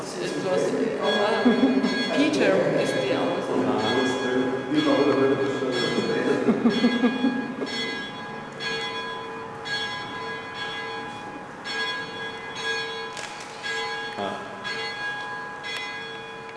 Listen to the Bell You can hear my camera click as I take this picture.
wiedenhofenbell.wav